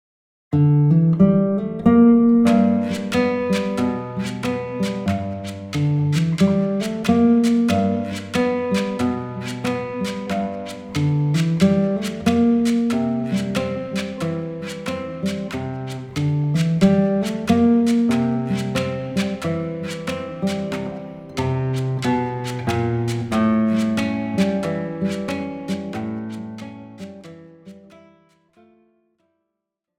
Besetzung: Gitarre